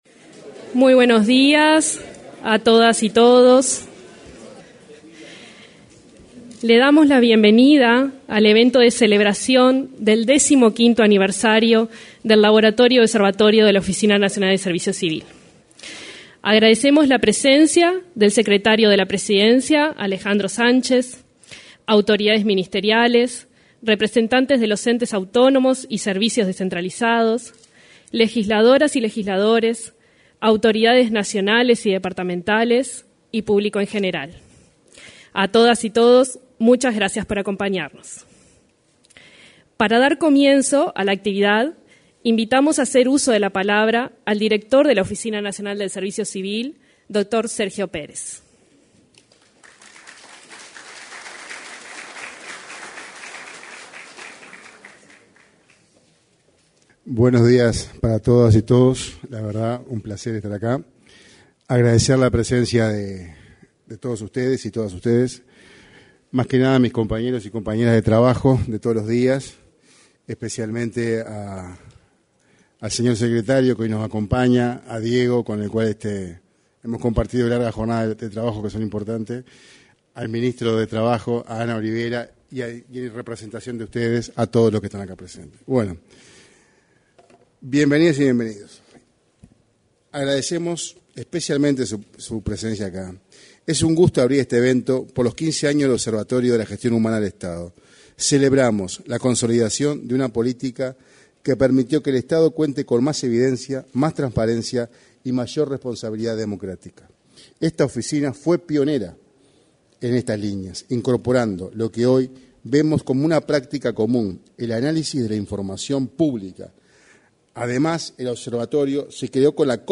Este viernes, en el salón de actos de la Torre Ejecutiva, se celebraron los 15 años del Observatorio de la Gestión Humana del Estado.
En la oportunidad, se expresaron: el director de la Oficina Nacional del Servicio Civil, Sergio Pérez, y el secretario de la Presidencia de la República, Alejandro Sánchez.